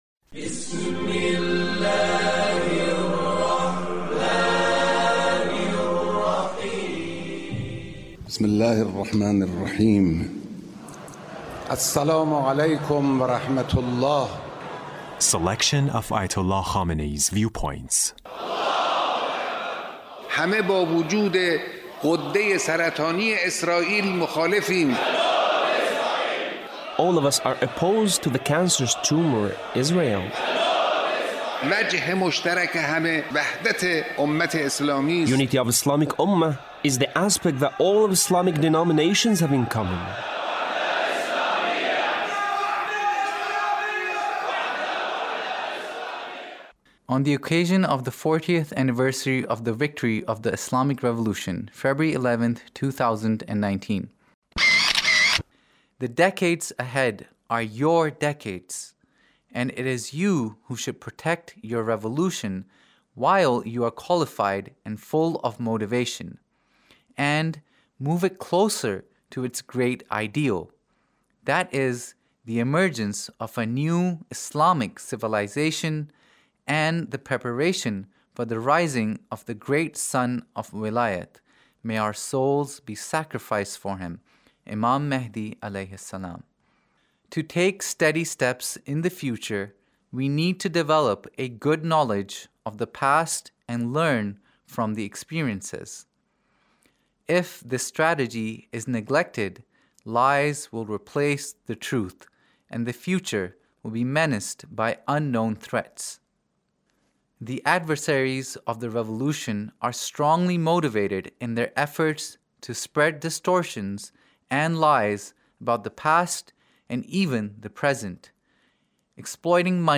Leader's Speech (1873)